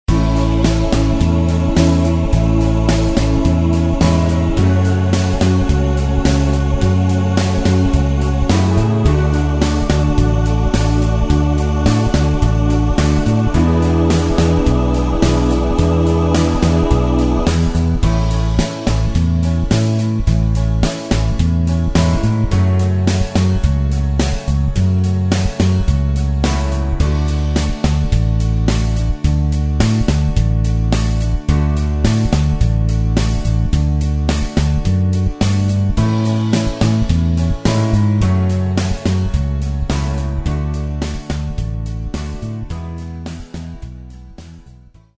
sans choeurs